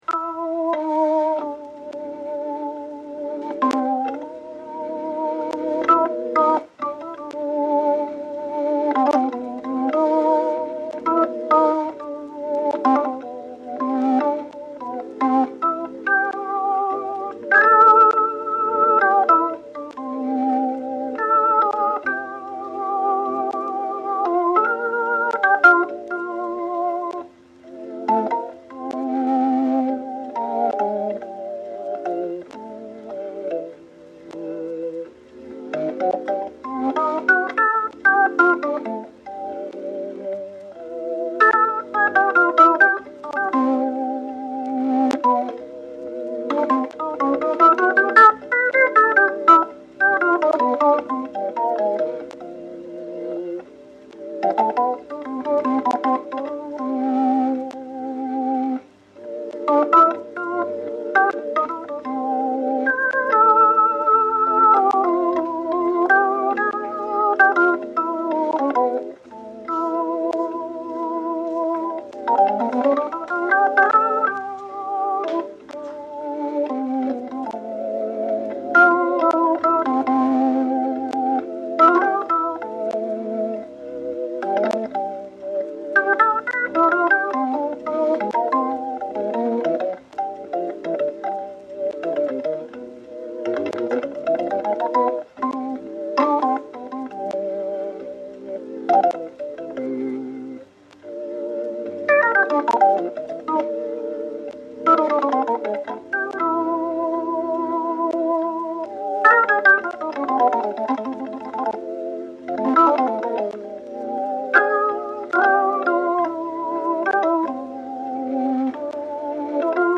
Le son est très bon au départ, pas du tout compressé comme je m'y attendrais avec du vieux.
Et j'ai l'impression qu'on l'a passé à la moulinette en rajoutant du souffle et des micro-coupures pour faire vinyl...
Bizarre !Prise de son étrangement bonne et présente pour un viel enregistrement.
Peut être un enregistrement de cette semaine avec ajout numérique de craquement de vinyle pour faire une bonne blague :wink:
unknoworganist.mp3